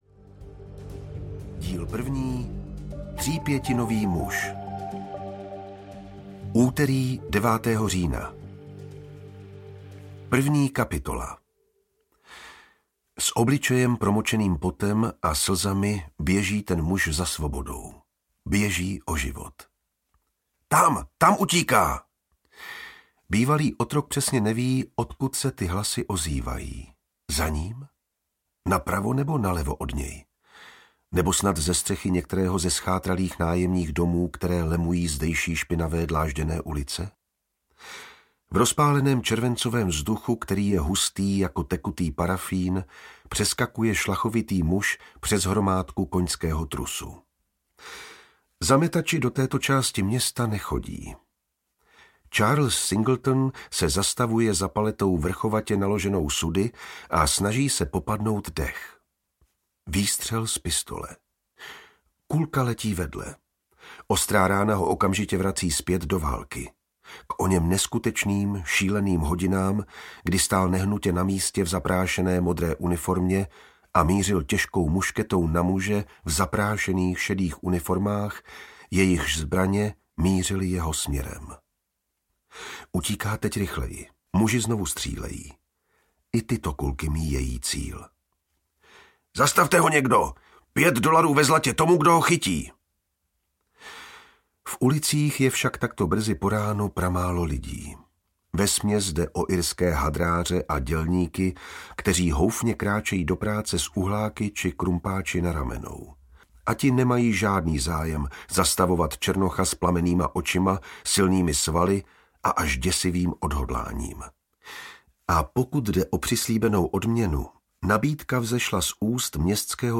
Dvanáctá karta audiokniha
Ukázka z knihy
• InterpretJan Vondráček